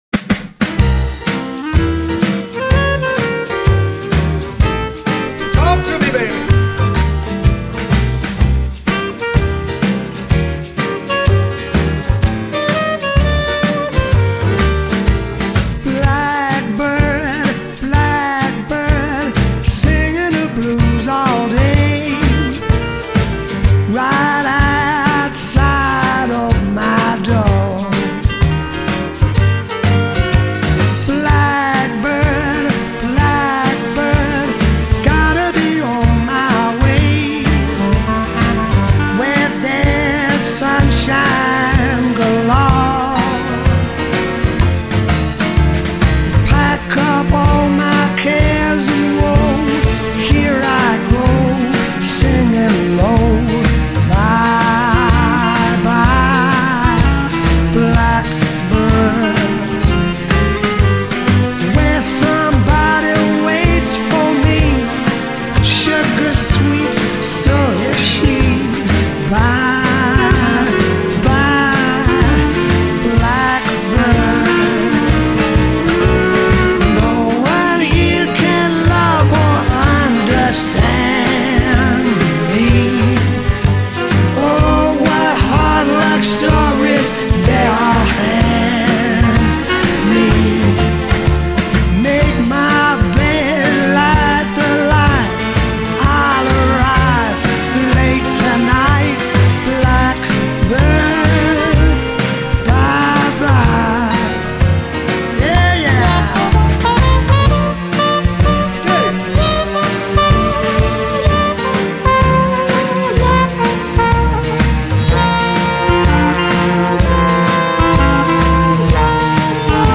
1926   Genre: Pop   Artist